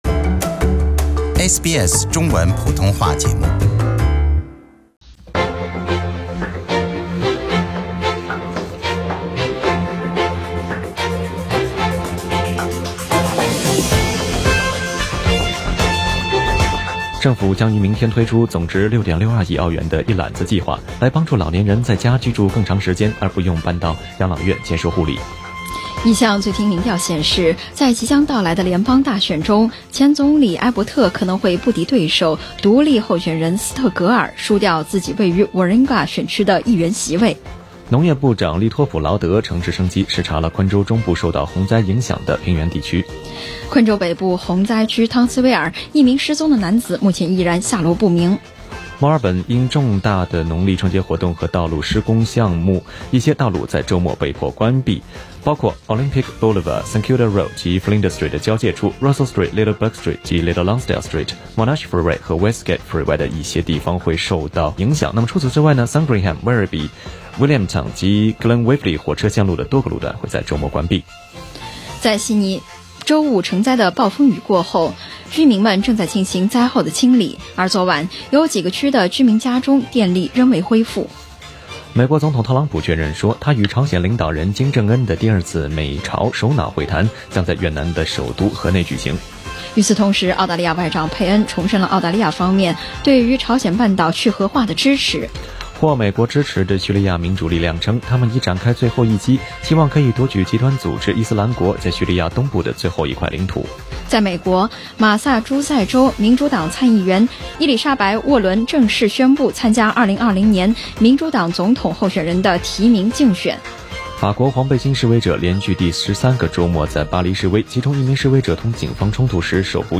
SBS Chinese News Source: SBS Mandarin